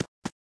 AV_footstep_runloop.ogg